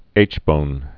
(āchbōn)